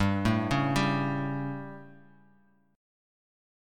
Gsus2b5 chord